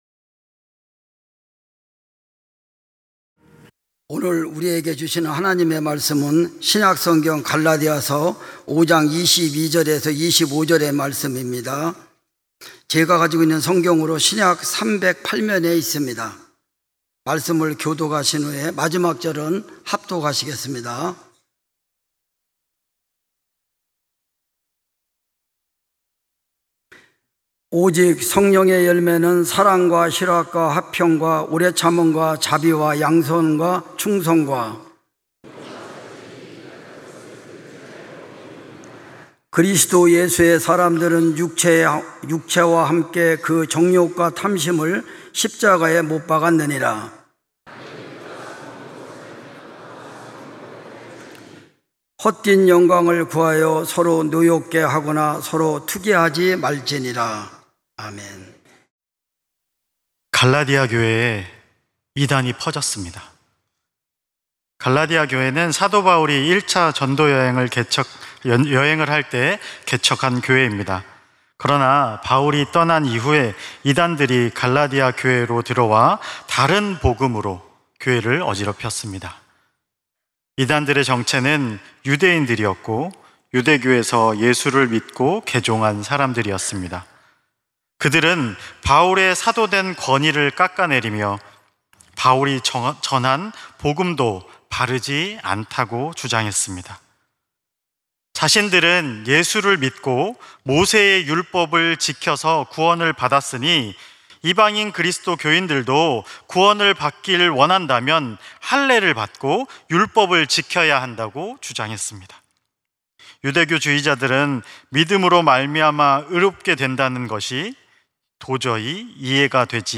찬양예배 - 우리가 성령으로 살면